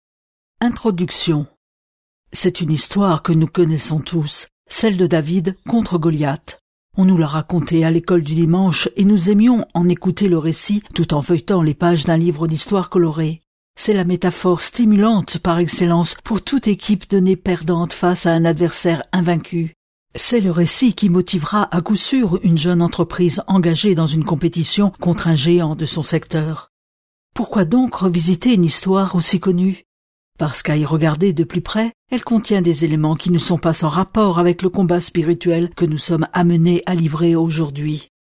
Diffusion distribution ebook et livre audio - Catalogue livres numériques
Lire un extrait - Sans audace pas de couronne - Terrassez les géants d'aujourd'hui de Frère André